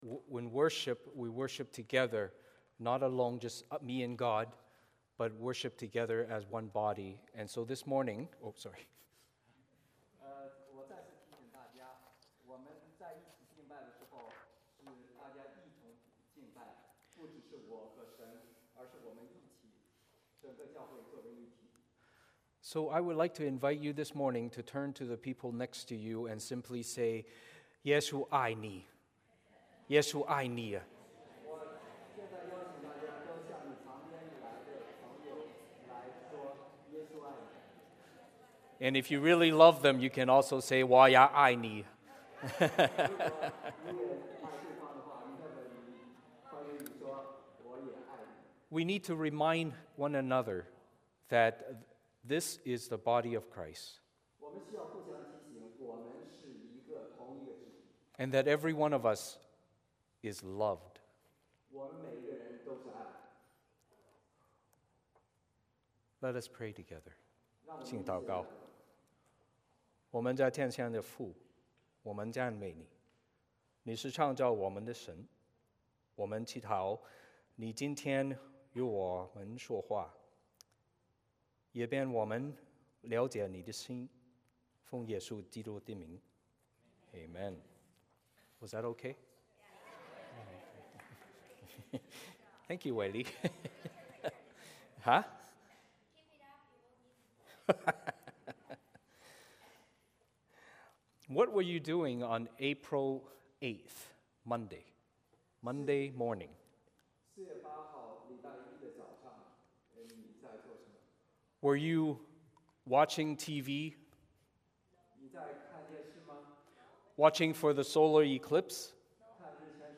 Passage: 使徒行传 15:22-29 (圣经当代译本) Service Type: 主日崇拜 欢迎大家加入我们的敬拜。